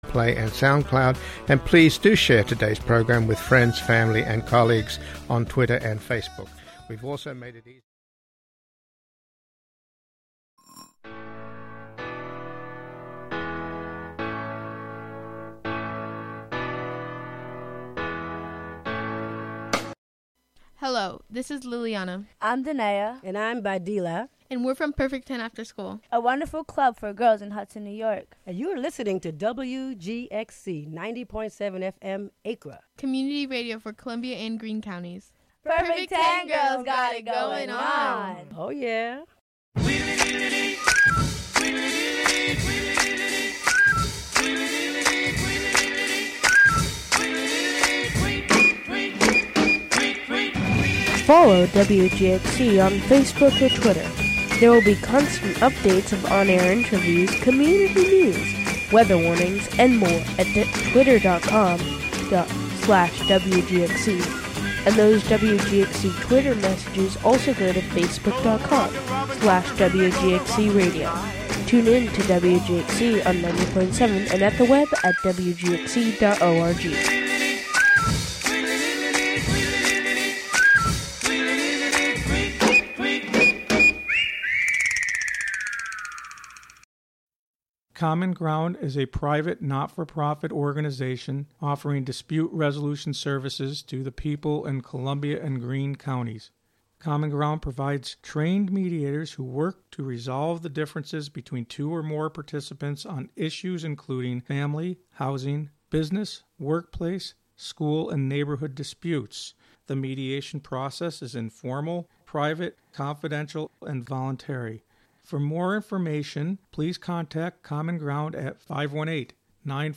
"All Together Now!" is a daily news show covering...